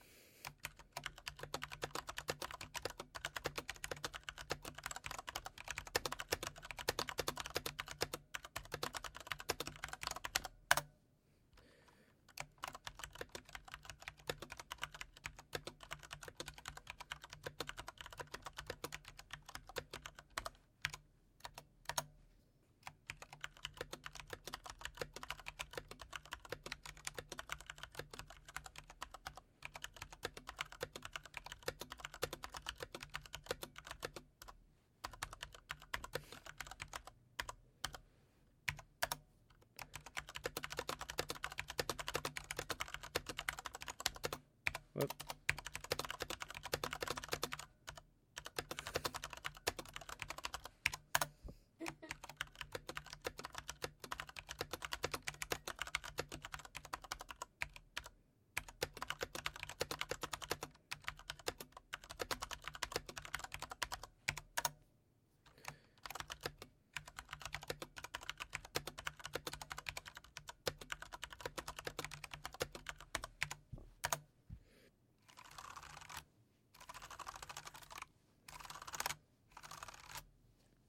FilcoNinja-BR-PBT.mp3